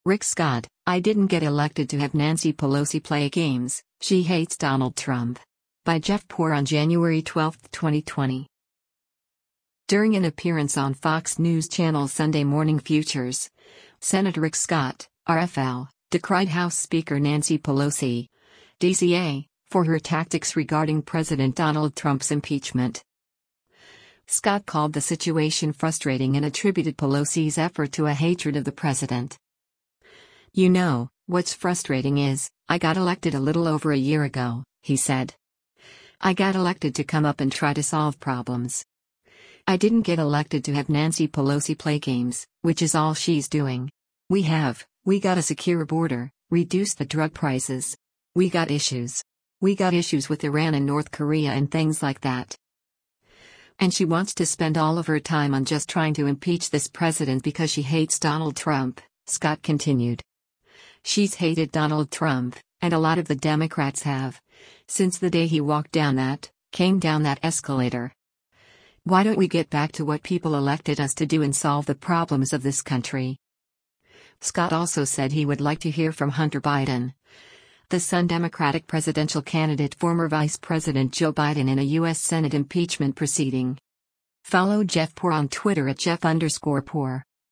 During an appearance on Fox News Channel’s “Sunday Morning Futures,” Sen. Rick Scott (R-FL) decried House Speaker Nancy Pelosi (D-CA) for her tactics regarding President Donald Trump’s impeachment.